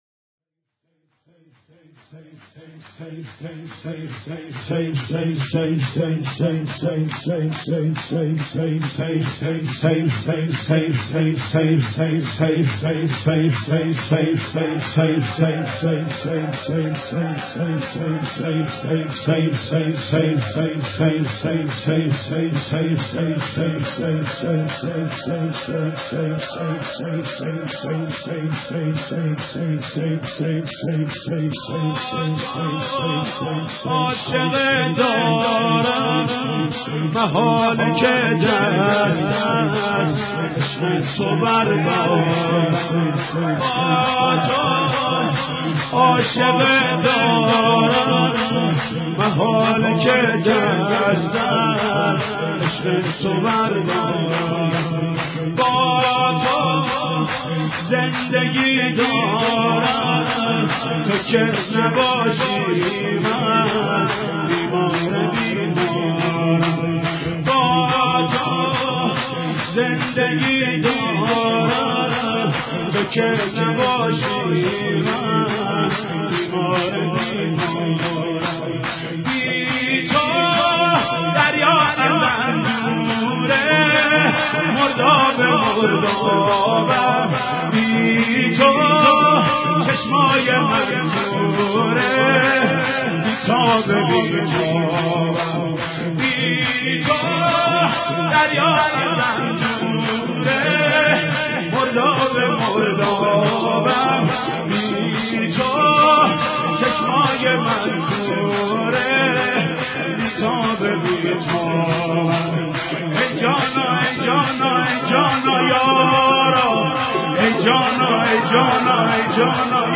شور احساسی بسیارزیبای امام حسین(ع)، به نفس حاج محمود کریمی -(محاله که دست از عشق تو بردارم..)